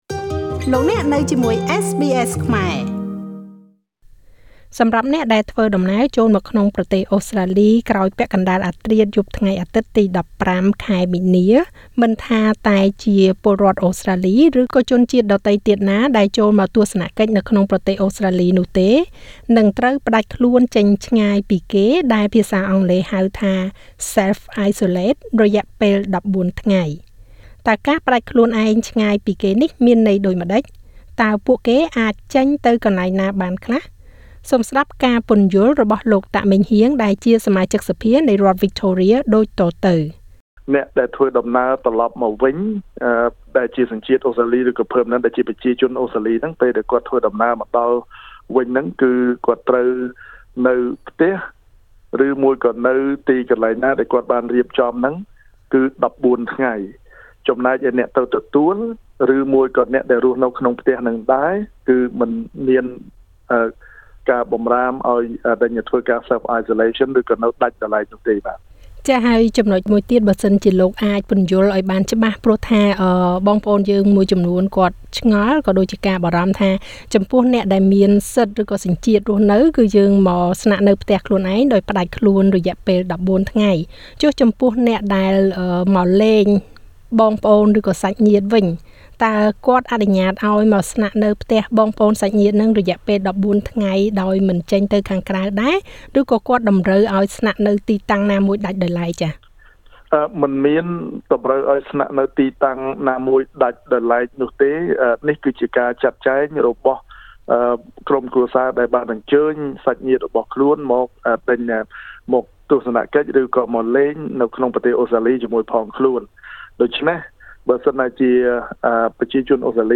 សូមស្តាប់ការពន្យល់របស់លោក តាក ម៉េងហ៊ាង សមាជិកសភានៃរដ្ឋវិចថូរៀដូចតទៅ។